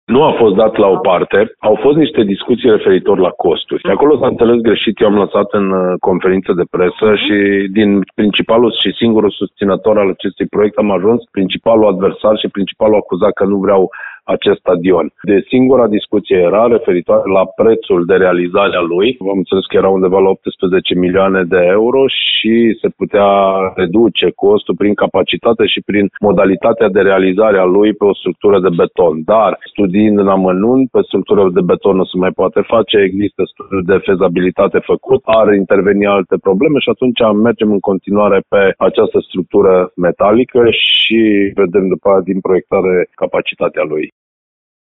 Viceprimarul Cosmin Tabără vrea ca bugetul local să cuprindă investiţia: